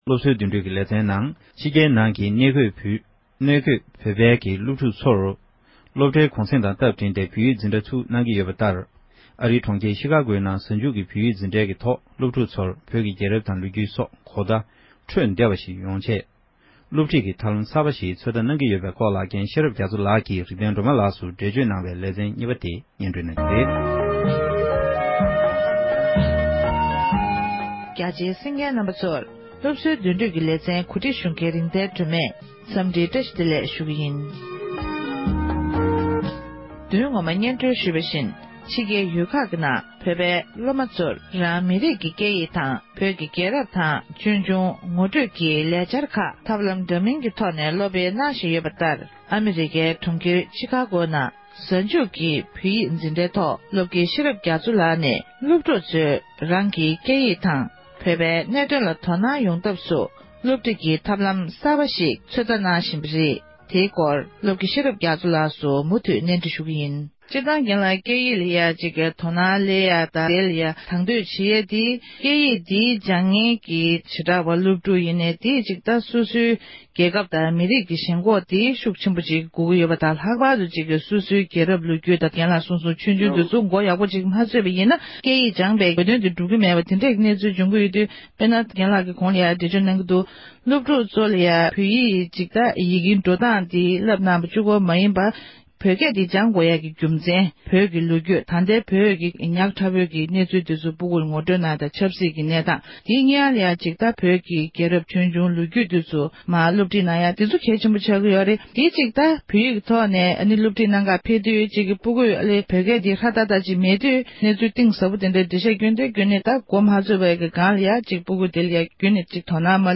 གནས་འདྲི་ཞུས་པའི་ལེ་ཚན་གྱི་དུམ་མཚམས་གཉིས་པ་དེ་གསན་རོགས་གནང༌༎